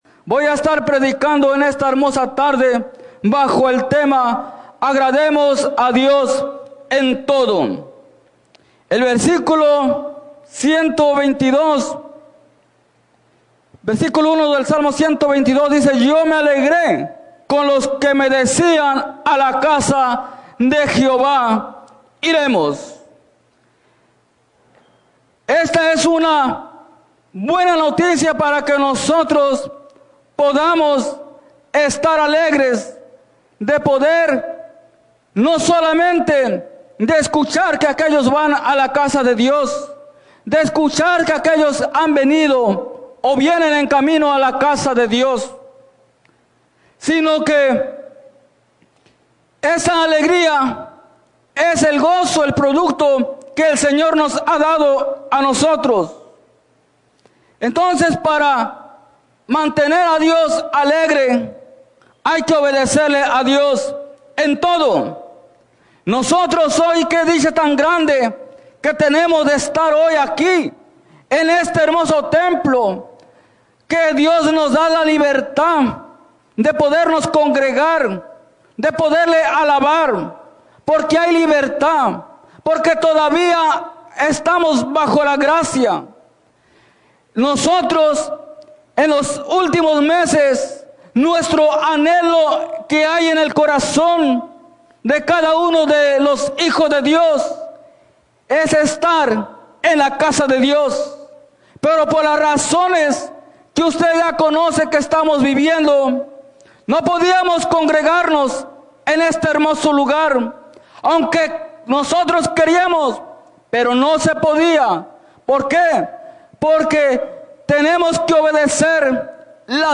en la Iglesia Misión Evangélica en Norristown, PA